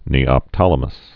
(nēŏp-tŏlə-məs)